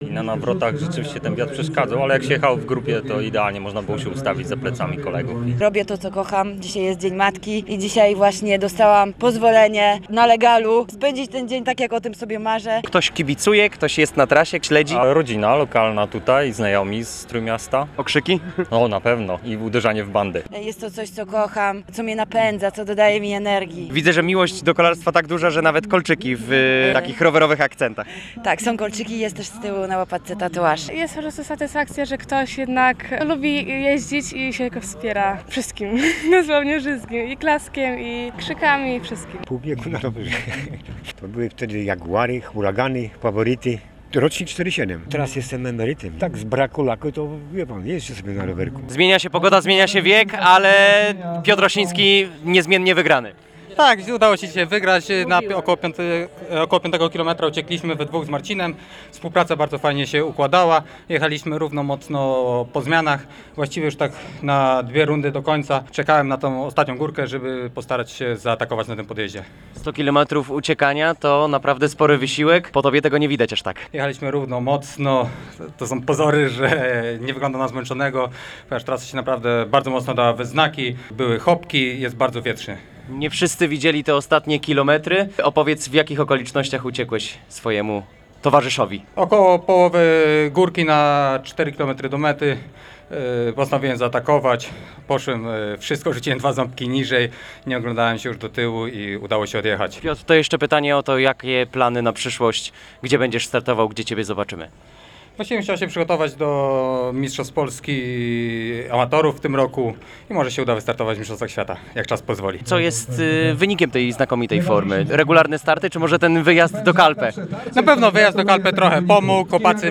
Posłuchaj materiału z wydarzenia i rozmowy